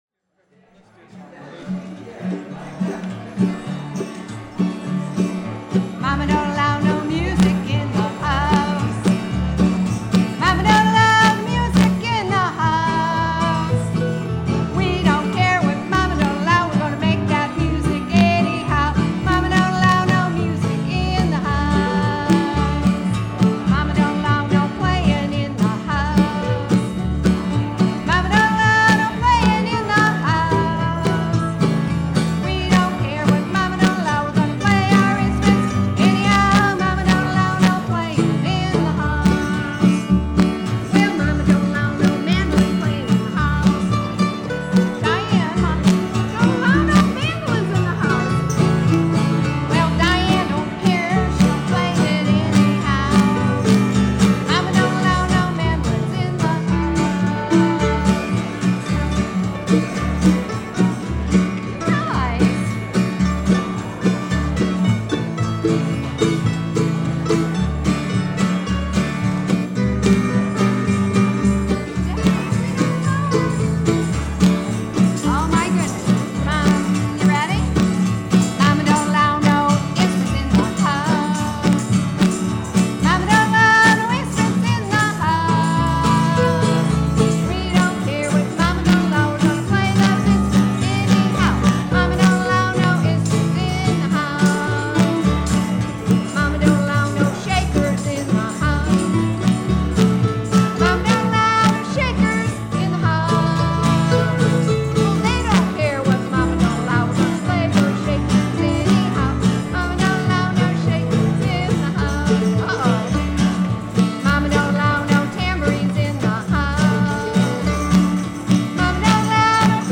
I could use some help figuring out the cause of a noise problem that cropped up last weekend when my bluegrass band performed at a local indoor farmers market.
The issue is a loud snapping noise that can be heard in some of the recordings at moments where the sound level produced by the singer or instrument is high. To me, the noise sounds electrical, like static or a short, but I'm no expert.
View attachment 22 Mama Don't Allow 2018-02-03.mp3 - very noticeable in first minute over vocal